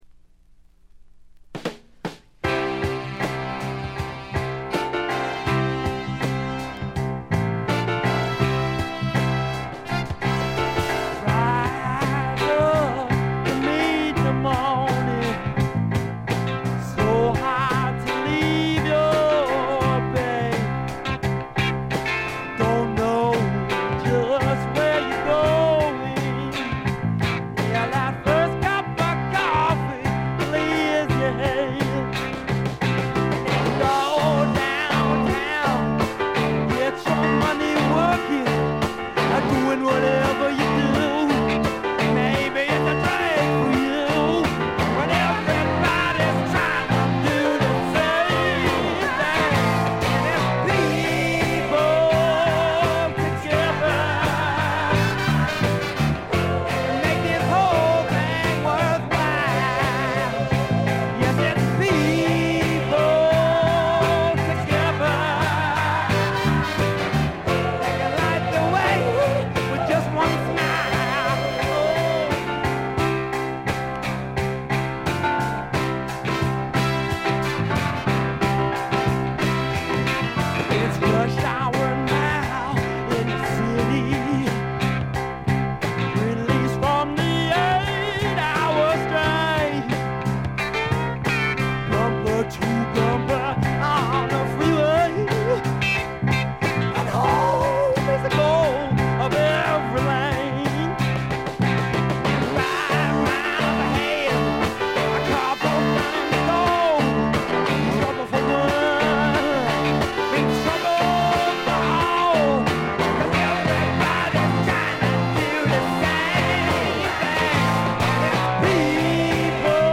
軽微なチリプチ程度（B1冒頭だけちょっと目立つ）。
恐ろしい完成度を誇るメンフィス産の名作にしてスワンプロックを代表する名盤。
まさしくスワンプロックの理想郷ですね。どこからどこまで、どこを切っても完璧なメンフィス・サウンドです。
試聴曲は現品からの取り込み音源です。
Vocals, Acoustic Guitar, Piano, Violin
BACKGROUND VOCALS